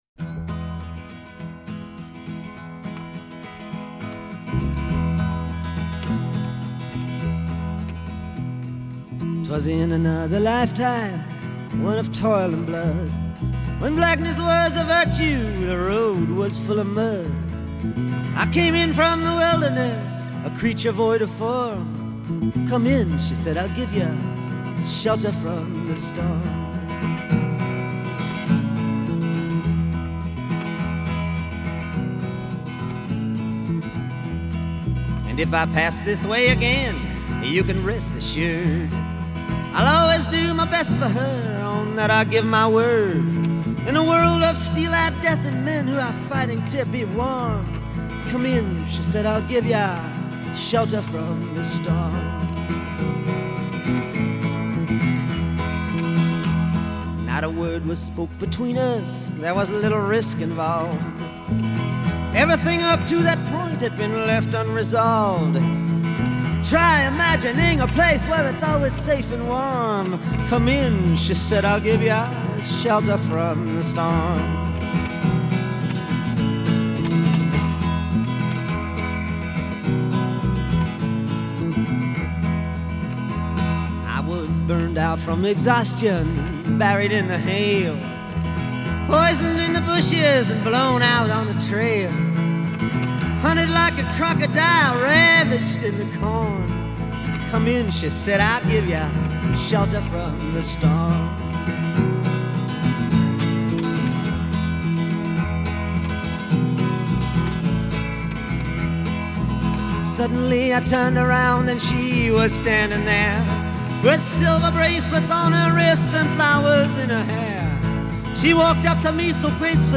Жанр: Classic Rock